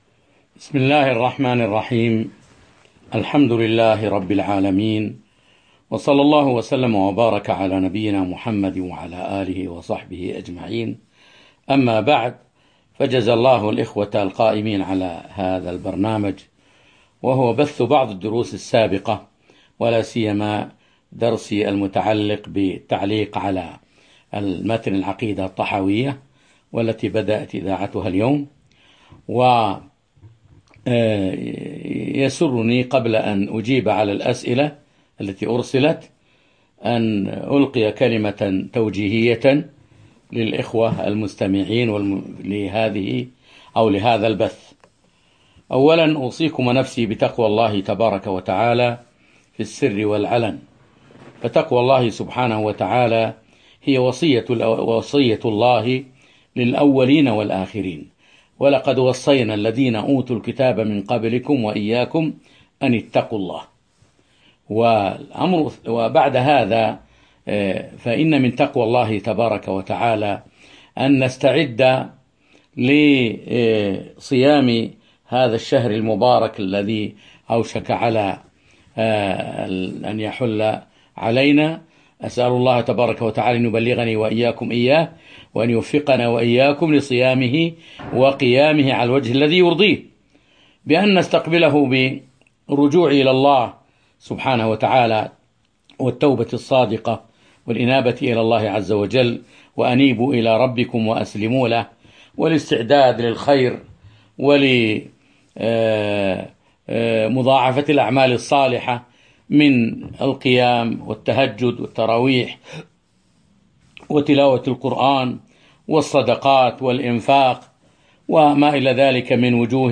اللقاء المفتوح الأول ضمن دورة الخليفة الراشد علي بن أبي طالب 1441 هـــ عبر البث المباشر